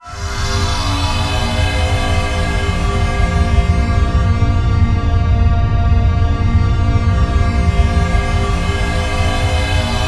Index of /90_sSampleCDs/E-MU Producer Series Vol. 3 – Hollywood Sound Effects/Science Fiction/Brainstem